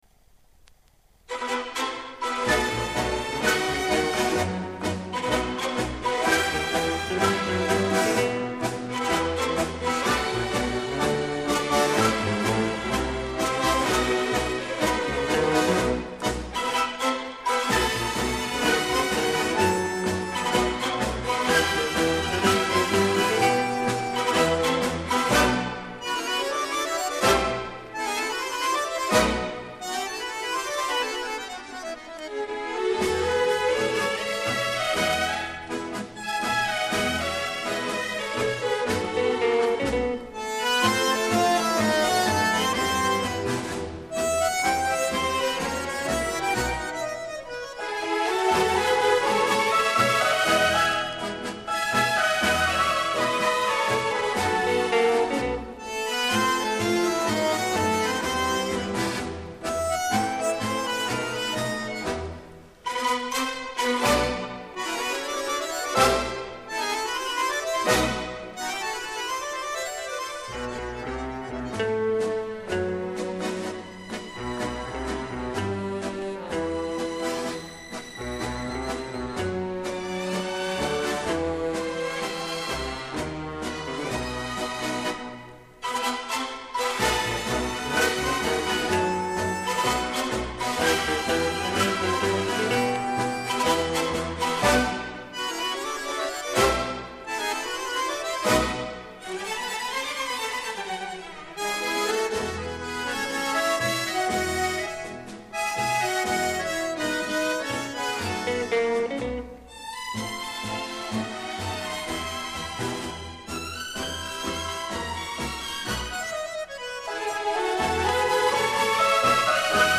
С пластинки